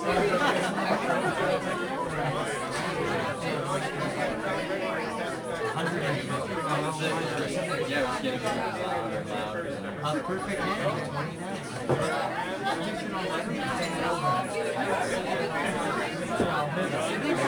lounge.ogg